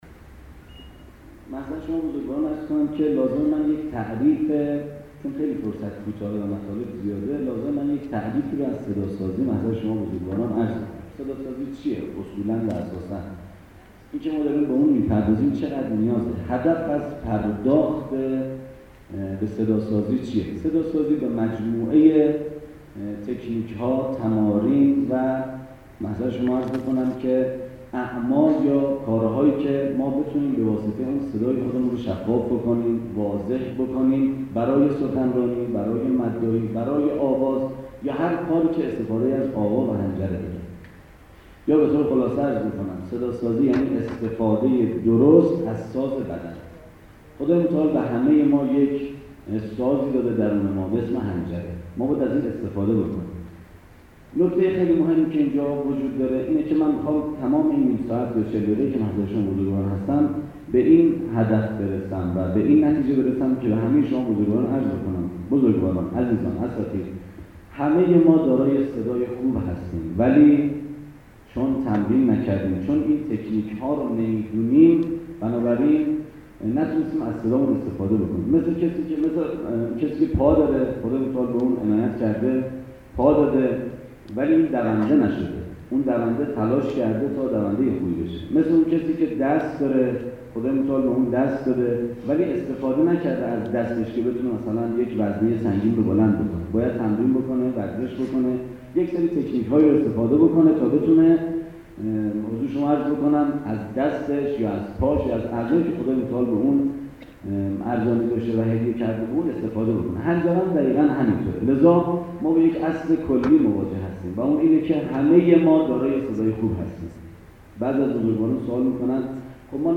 به گزارش خبرنگار خبرگزاری رسا، کارگاه آموزشی یک روزه «فن رثا» به همت کارگروه هنرهای آسمانی معاونت تبلیغ حوزه های علمیه امروز در سالن همایش های دارالشفاء برگزار شد.